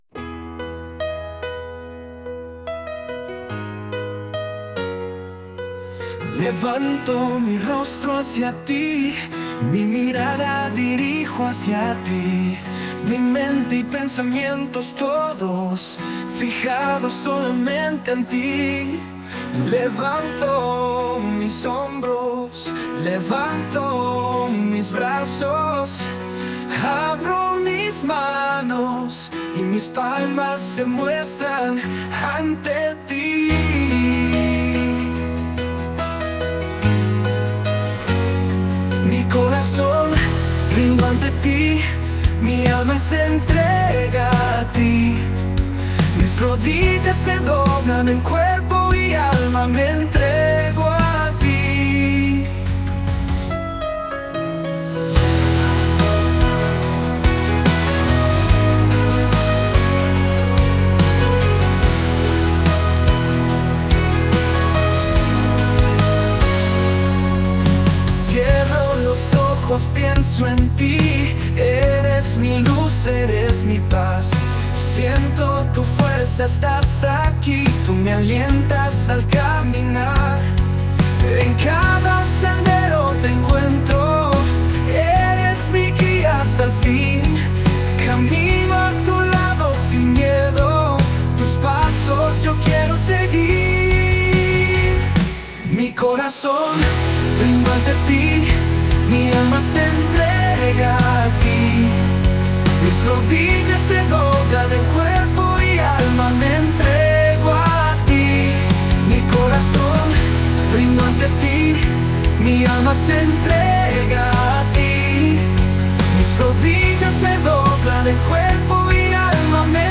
Rock Suave